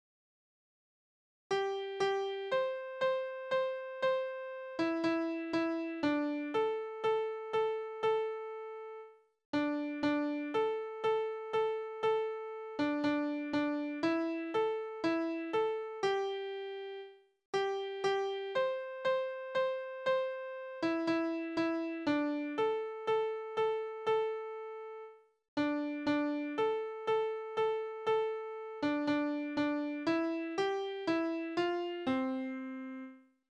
Hamburger Melodie (Kreuzpolka) Tanzverse: Kreuzpolka Tonart: C-Dur Taktart: 4/4 Tonumfang: Oktave Besetzung: instrumental